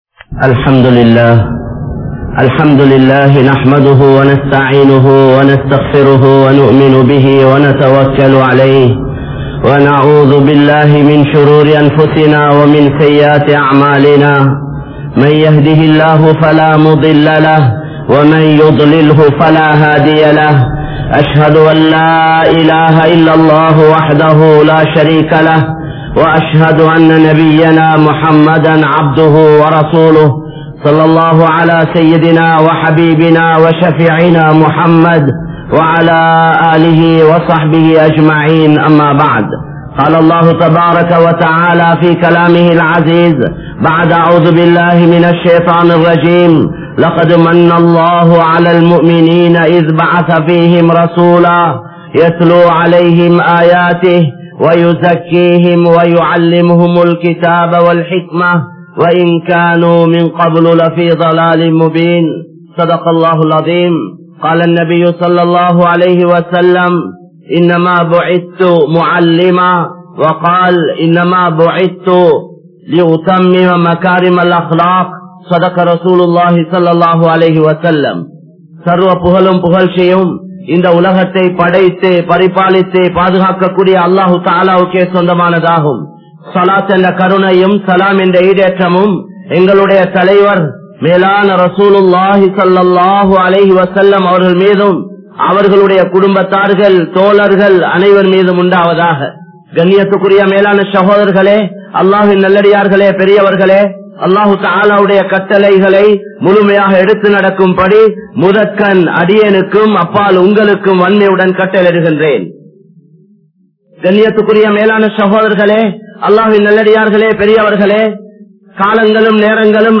Ulahaththukkaaha Valaatheerhal (உலகத்துக்காக வாழாதீர்கள்) | Audio Bayans | All Ceylon Muslim Youth Community | Addalaichenai
Kollupitty Jumua Masjith